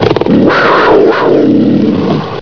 Make me GROWL!!!
growl.wav